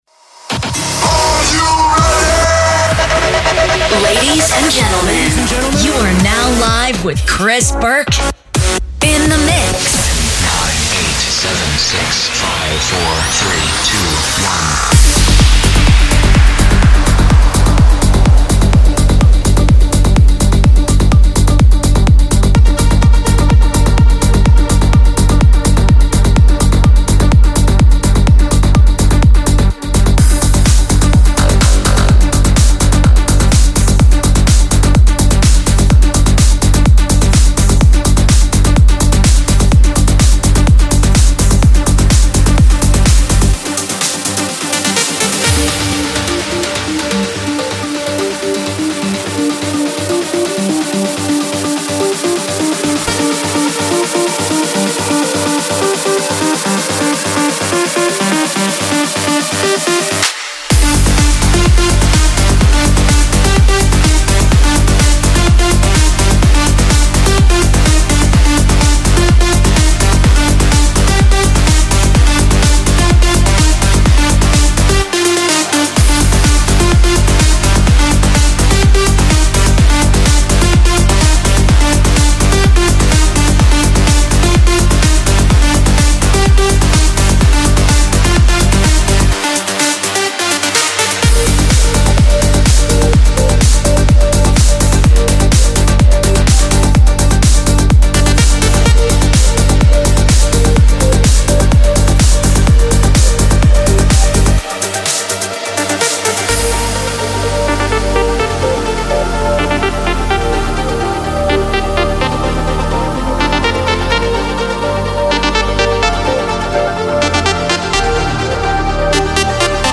promo set